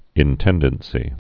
(ĭn-tĕndən-sē)